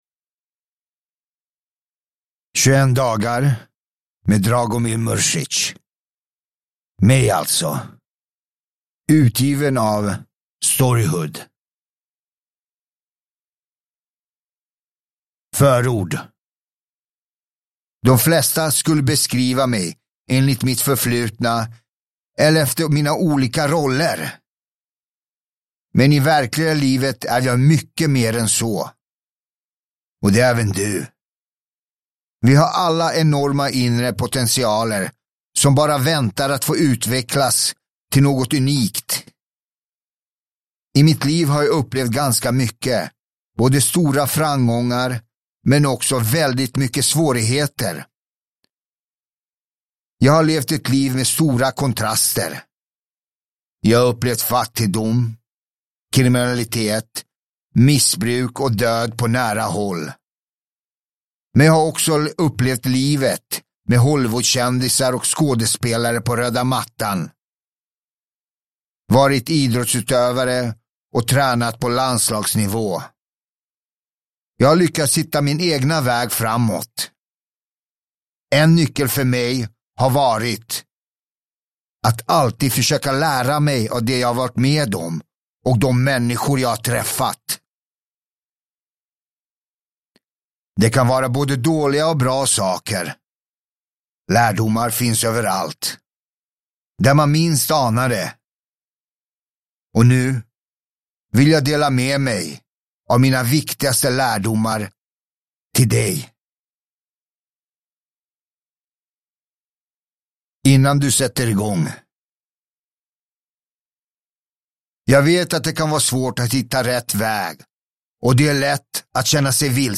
Uppläsare: Dragomir Mrsic
Ljudbok